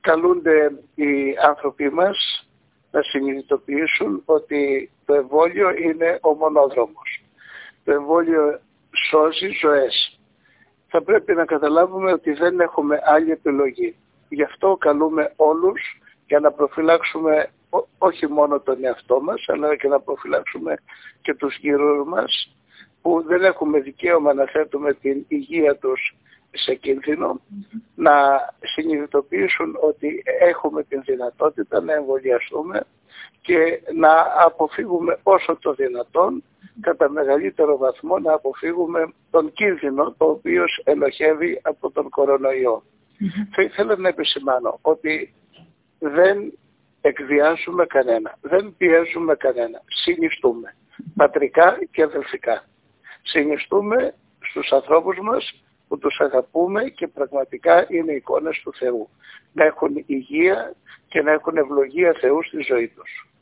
Το μήνυμα του Μητροπολίτη έχει ως εξής: